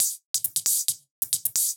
Index of /musicradar/ultimate-hihat-samples/135bpm
UHH_ElectroHatD_135-02.wav